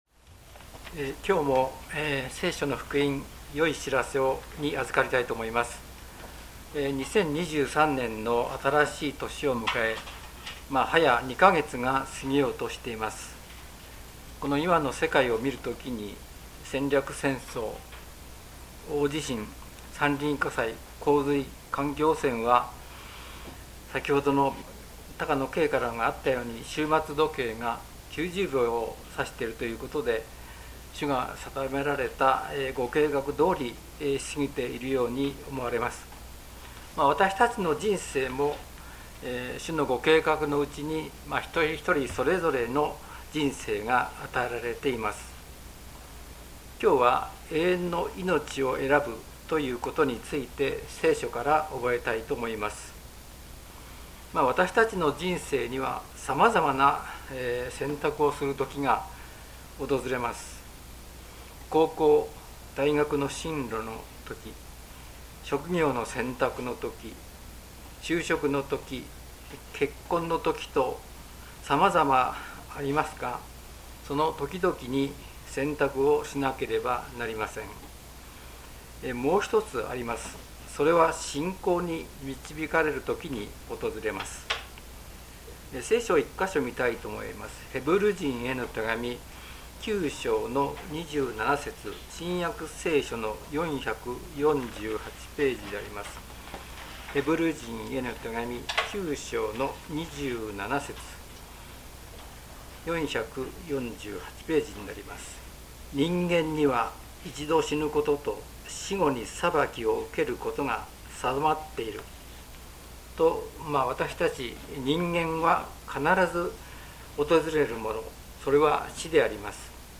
聖書メッセージ No.152